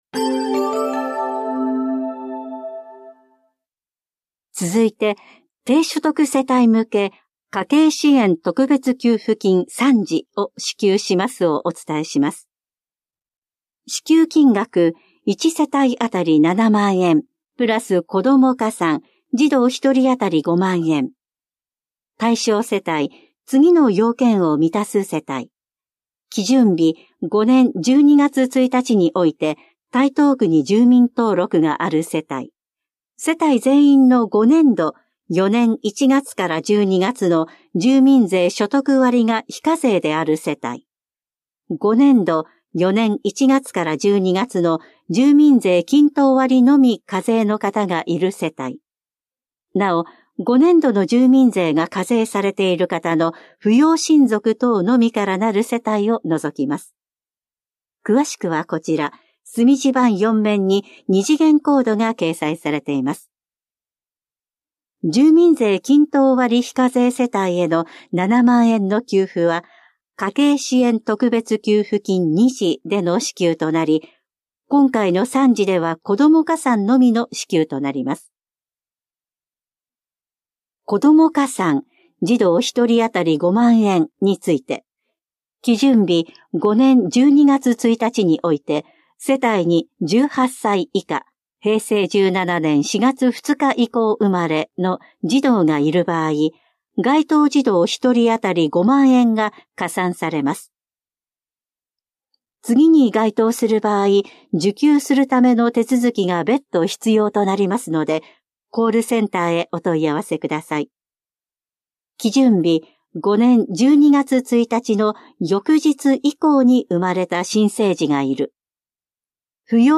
広報「たいとう」令和6年2月20日号の音声読み上げデータです。